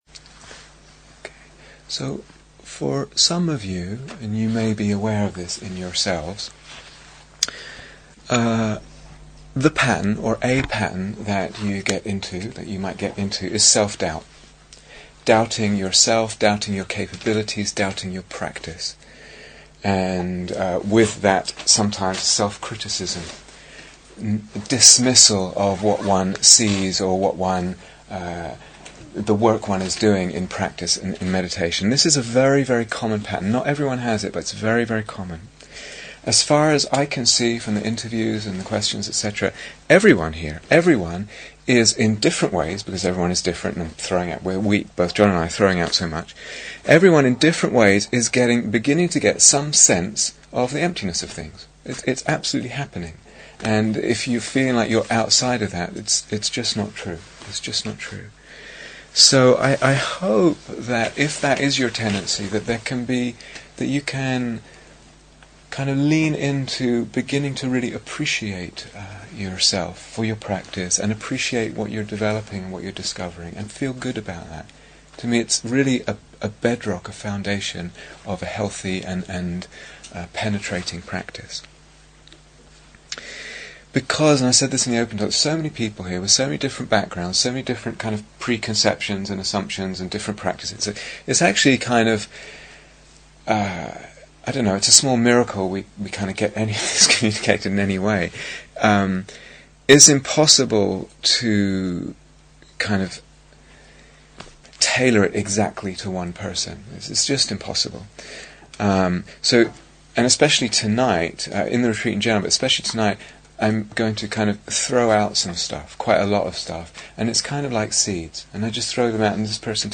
Here is the full retreat on Dharma Seed Please note that these talks are from a 4 week retreat for experienced meditators. The talks and meditations can be listened to in any order or individually, but as they progressively unfold different levels of understanding of Emptiness, they will probably be more fully understood and the practices more easily developed if taken in series.